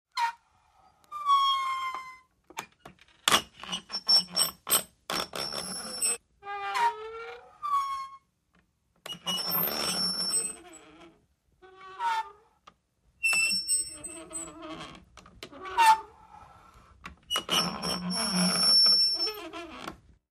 MACHINES - CONSTRUCTION HAND MANUAL WATER PUMP: INT: Slow squeaking and creaking.